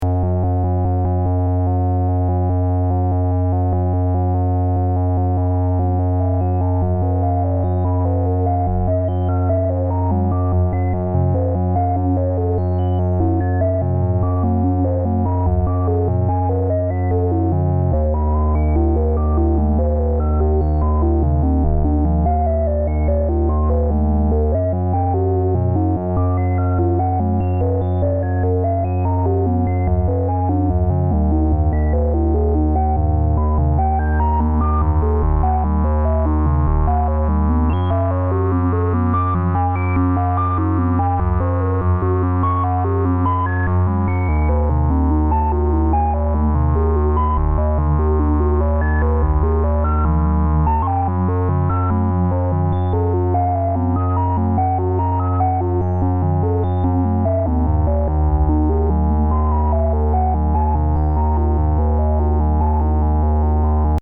Hier ein Beispiel, wie ich es mir vorstelle: Anhang anzeigen S&H Beispiel.mp3 Ein VCO spielt zwei Töne von Freestylo, durch ein Filter, welches von S&H moduliert wird (und dann von Hand aufgedreht). Der selbe S&H steuert die Tonhöhe vom zweiten VCO. Der Input vom S&H kommt von LFO und Euclidian.